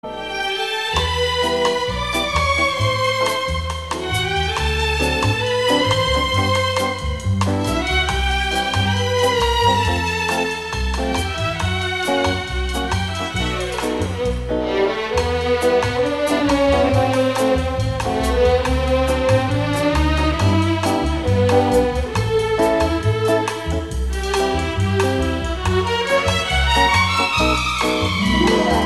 Категория: Саундтреки | Дата: 28.11.2012|